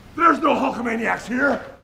no hulkamaniacs here Meme Sound Effect